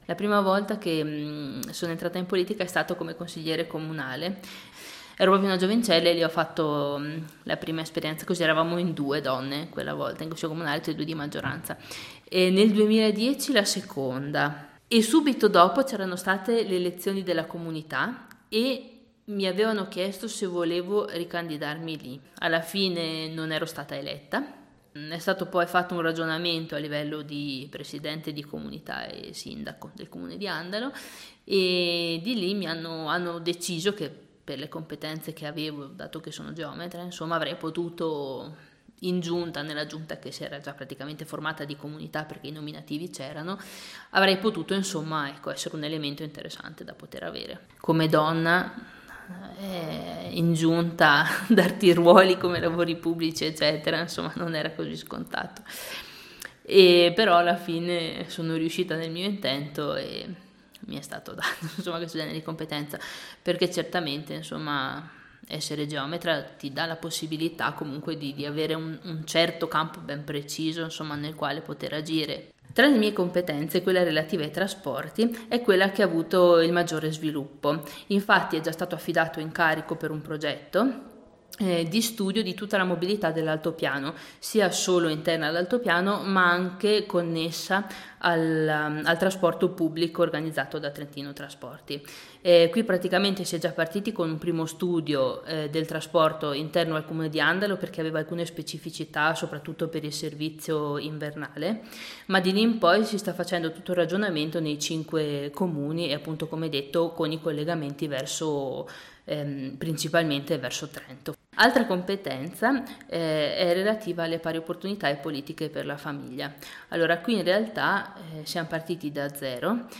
Presentazione audio dell'assessore Pamela Bottamedi